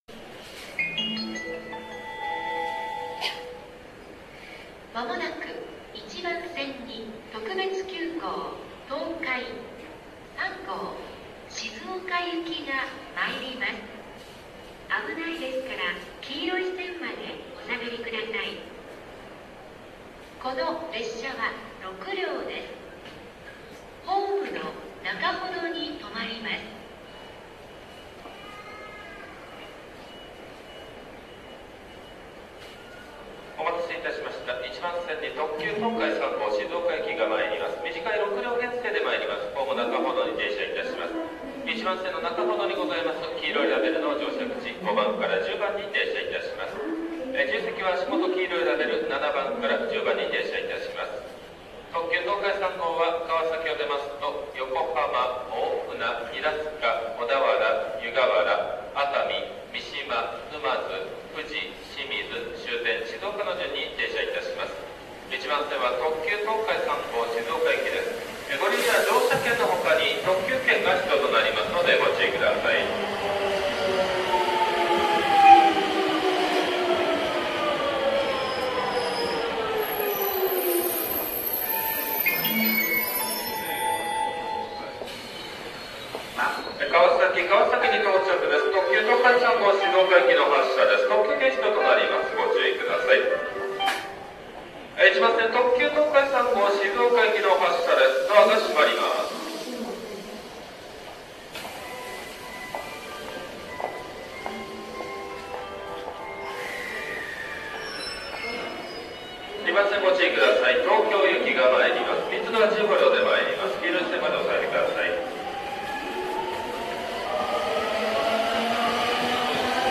走行音収録の合間などに録った音、偶然マイクに入った音などを、特に意味もなく公開していきます。
こちらは所変わって川崎駅です。ホームに上がったところ、偶然廃止直前の特急（ワイドビュー）東海号が入ってきました。
川崎駅特急（ワイドビュー）東海号（373系）発着風景